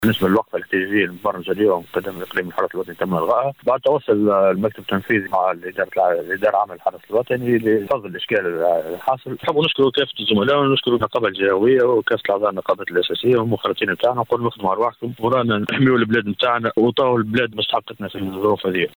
في تصريح ل ‘ام اف ام”.